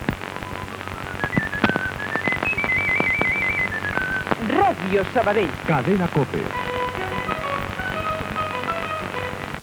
Indicatiu de l'emissora i de la cadena COPE.